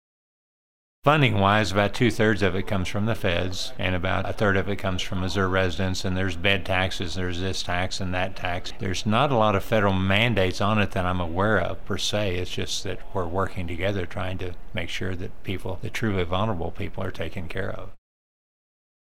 JEFFERSON CITY — State Sen. Mike Cunningham, R-Rogersville, discusses Medicaid funding and its relation to Missouri’s operating budget.